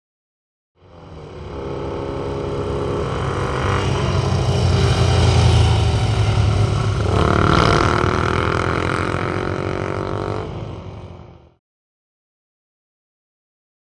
Motorcycles; By; Eight Motorbikes Up And Past With Revs.